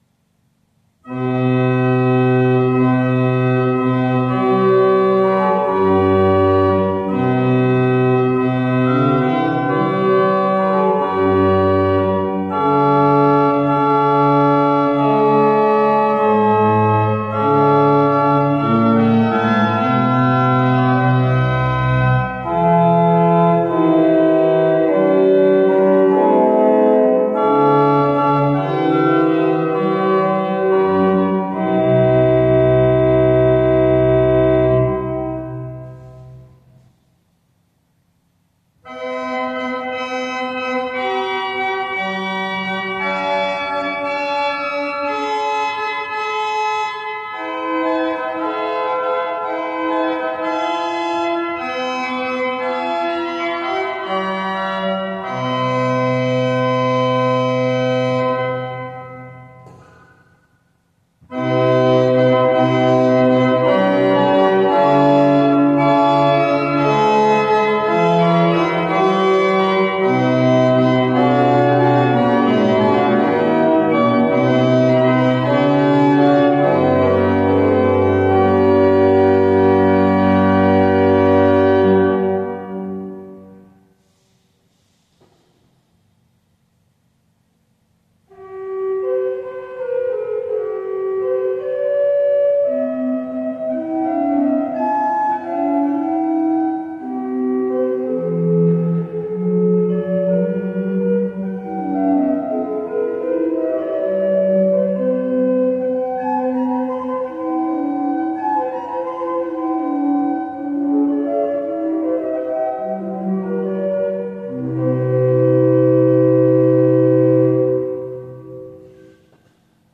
Velikost 2 manuály 14 rejstříků
Pneumatická traktura Membránová vzdušnice
Zvukové představení varhan (bez II. manuálu)
jedli-predstaveni-varhan.mp3